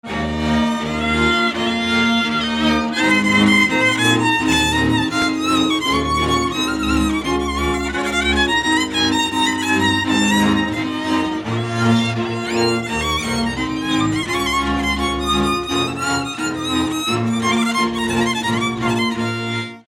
Dallampélda: Hangszeres felvétel
Erdély - Kis-Küküllő vm. - Teremiújfalu
hegedű
kontra (háromhúros)
bőgő
Műfaj: Lassú csárdás
Stílus: 3. Pszalmodizáló stílusú dallamok